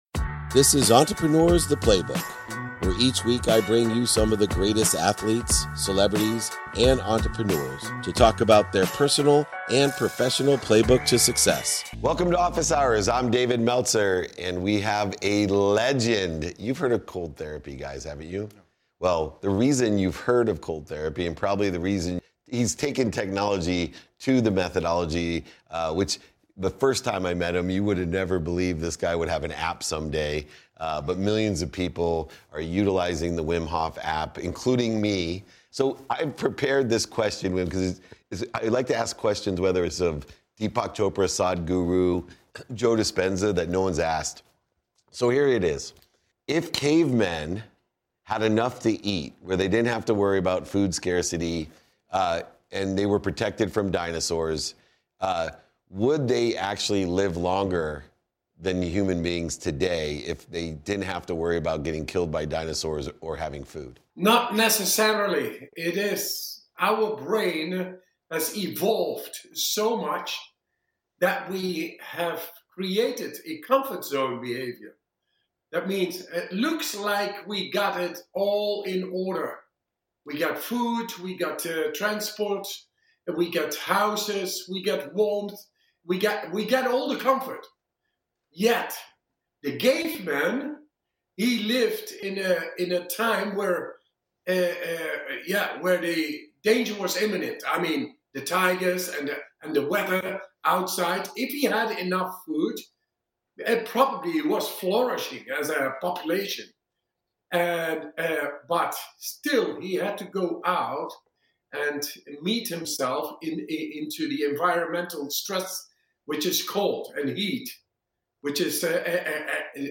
Dive into this enlightening conversation with the genius in cold exposure, Wim Hof, and discover the potential that lies in pushing ourselves outside our comfort zones.